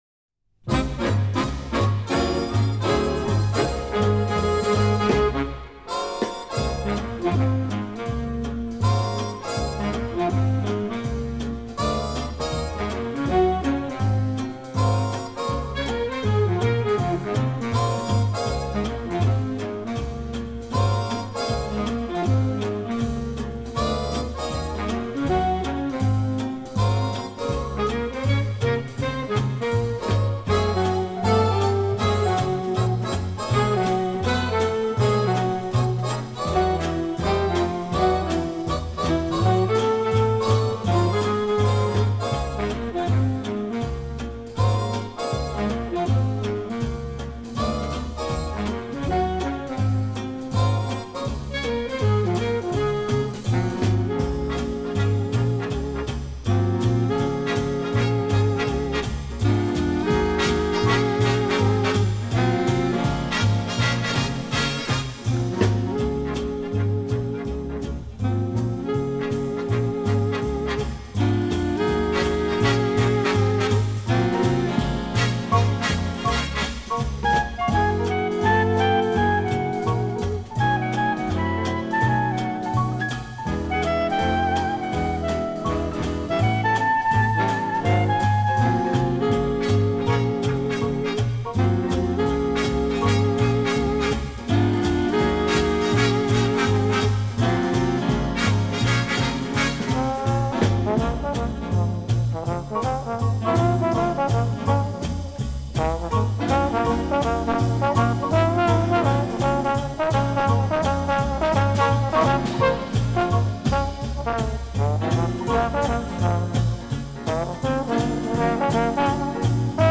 А так как мне почти всегда нравится своеобразное триединство ( клипповость)) - изображение (в данном случае -фото) + соответствующая музыка +  слова (найти самые подходящие слова - чуть ли не самое сложное)),  то и появилась ещё  и музыка - между спокойной оркестровой и джазом - предпочтение отдано джазу - а комментарии показались  ненужными.))